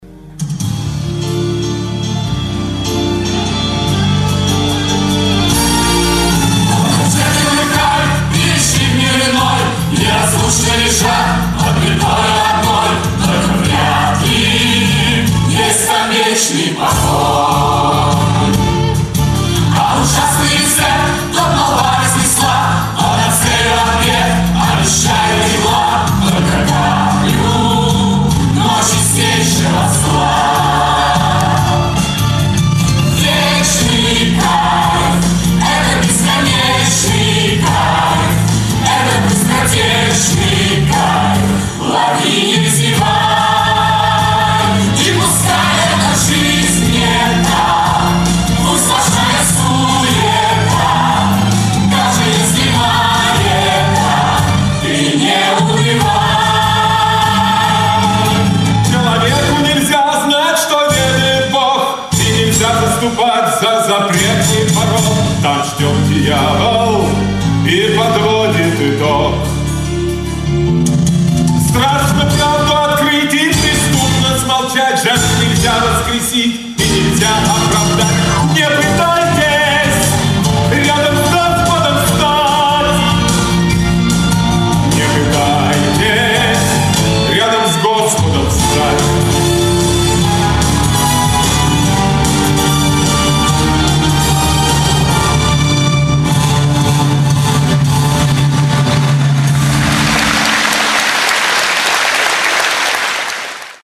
Музыкальная драма в 2-х частях
Аудио запись со спектакля от 26.05.2005 г*.